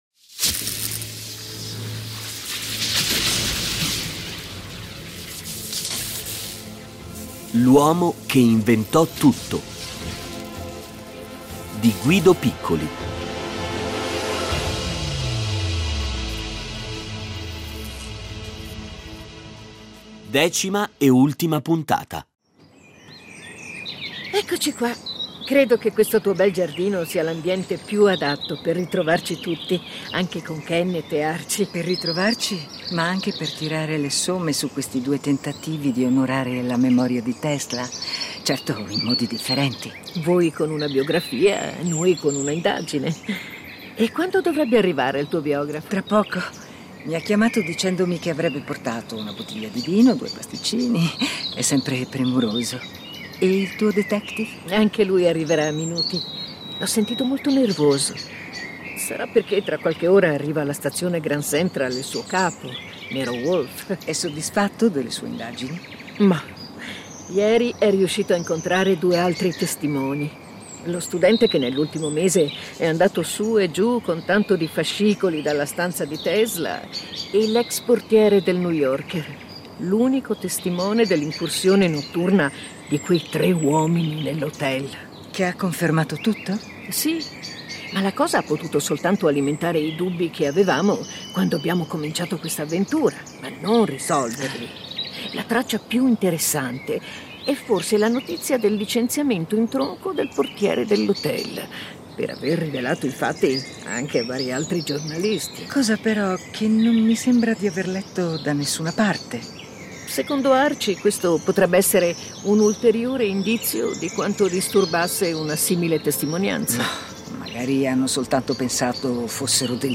Originale radiofonico di Guido Piccoli.
Nello sceneggiato compaiono familiari e vari amici dello scienziato, come Mark Twain, insieme con imprenditori, giornalisti, storici e altri tecnici e scienziati che lo frequentarono, a cominciare dal suo eterno grande nemico Thomas Edison.